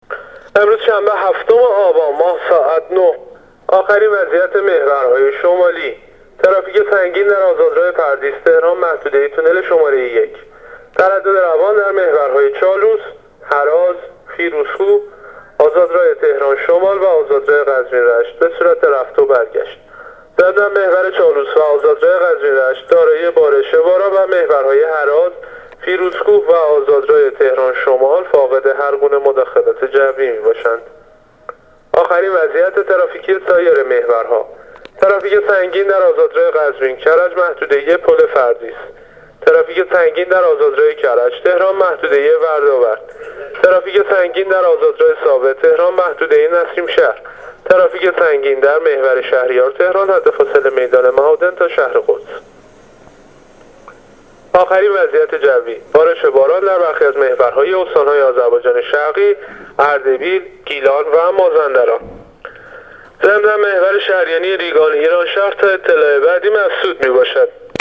گزارش رادیو اینترنتی از آخرین وضعیت ترافیکی جاده‌ها تا ساعت ۹ هفتم آبان؛